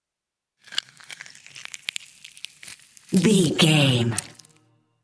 Creature eating flesh peel juicy
Sound Effects
scary
disturbing
horror